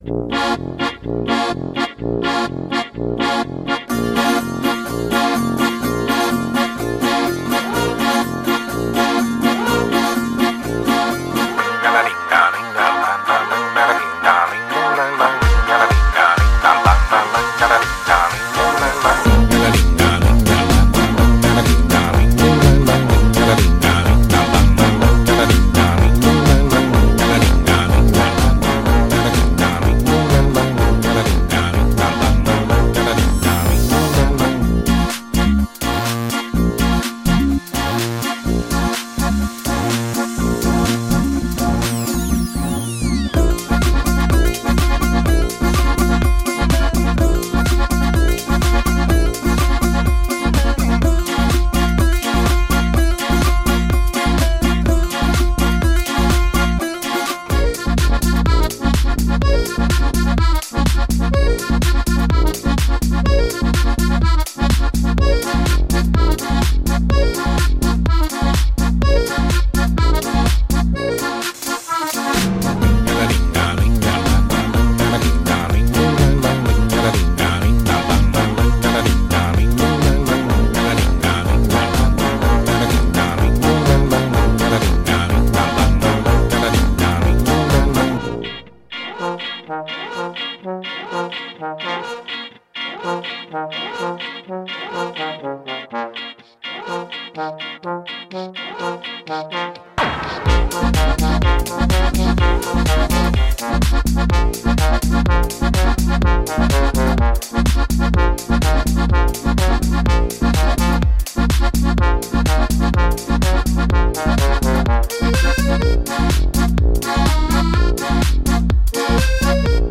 آهنگ تیتراژ با صدای